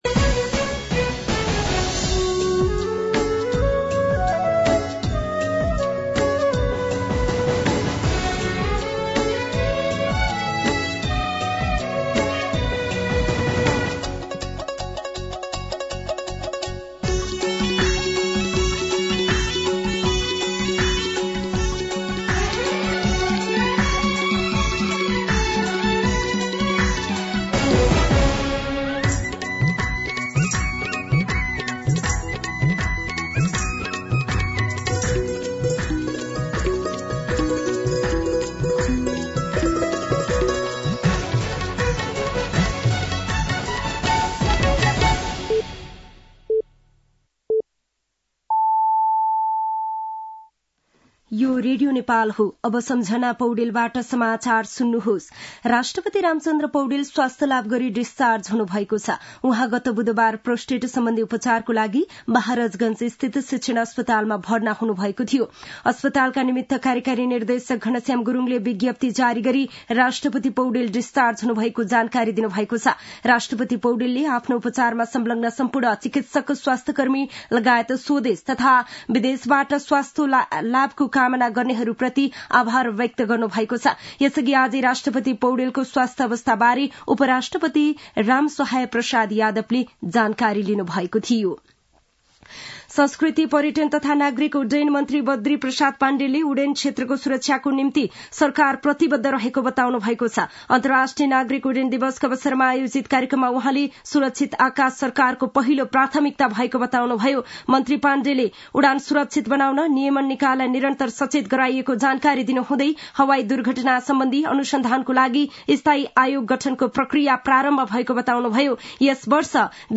दिउँसो ४ बजेको नेपाली समाचार : २३ मंसिर , २०८१
4-pm-Nepali-News.mp3